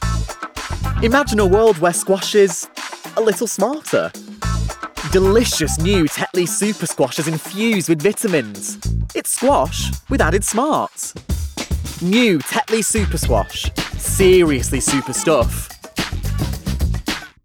Voice Reel
Tetley - Bright, Energetic